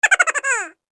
Tiny Cartoon Laugh Sound Effect
A short playful giggle sound effect adds a fun and whimsical touch to your projects. Perfect for animations, games, videos, and entertaining multimedia scenes, this cheerful giggle brings instant humor and charm.
Tiny-cartoon-laugh-sound-effect.mp3